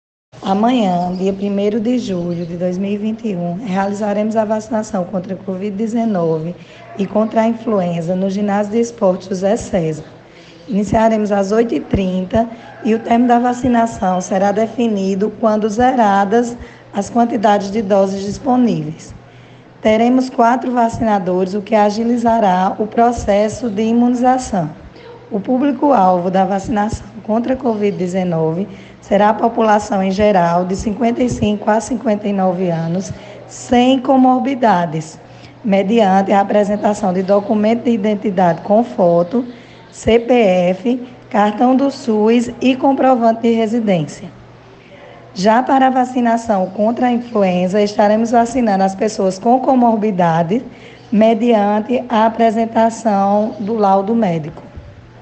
A informação foi dada pela secretária de Saúde, Rhyana Karla, nesta quarta-feira (30), no jornal Conexão com a Notícia , transmitido na Rádio Conexão FM de Santa Terezinha (104,9), ouça: https